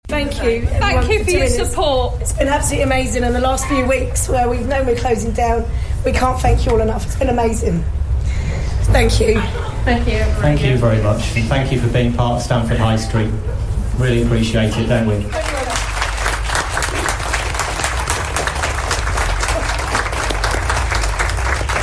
We organised a thank you with applause and cheers as the store, which has been on Stamford High Street for more than 40 years, closed its doors for the final time.